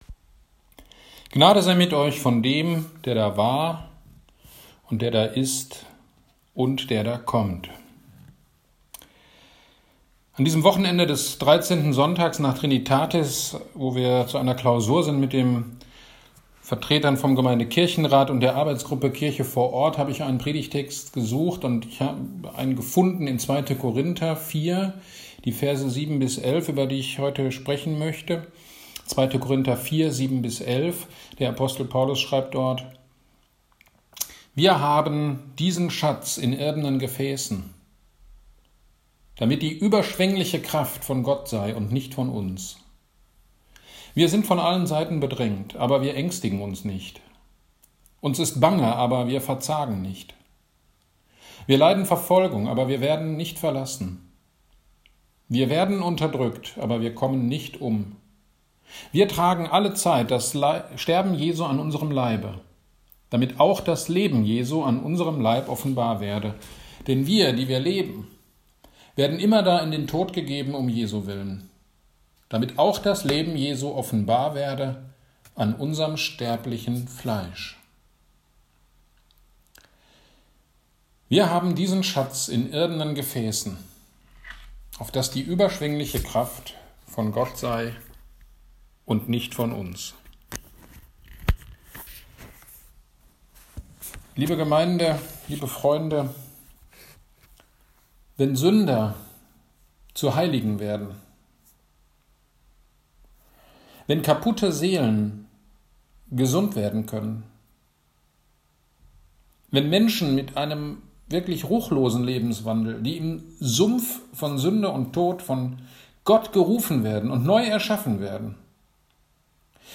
Predigt zu 2. Korinther 4,7-11. Schatz in irdenen Gefäßen